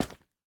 Minecraft Version Minecraft Version 1.21.5 Latest Release | Latest Snapshot 1.21.5 / assets / minecraft / sounds / block / candle / step1.ogg Compare With Compare With Latest Release | Latest Snapshot
step1.ogg